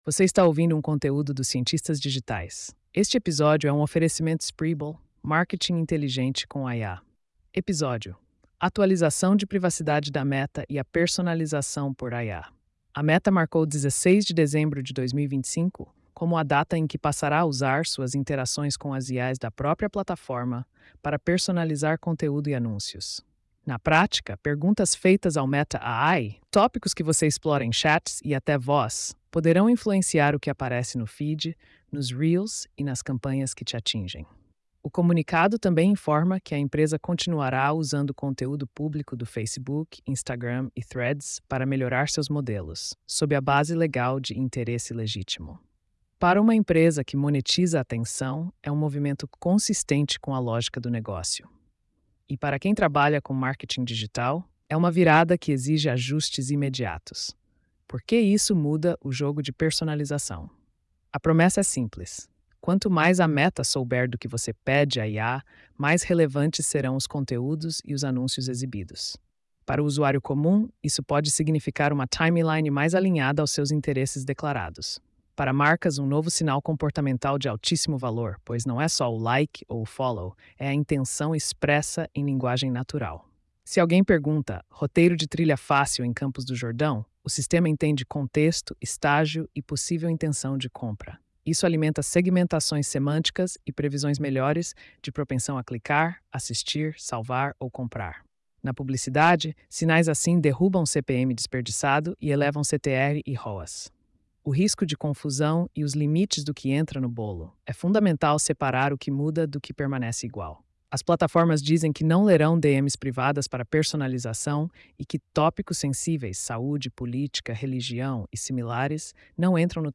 post-4539-tts.mp3